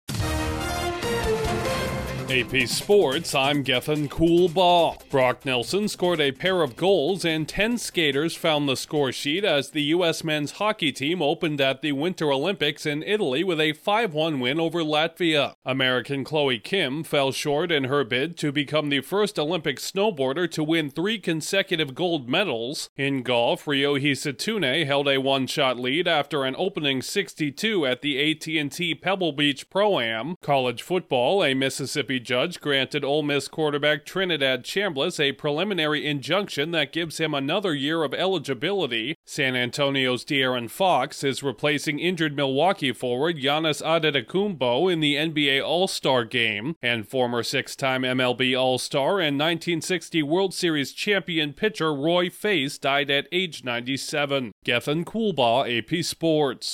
Sports News from the Associated Press / The latest in sports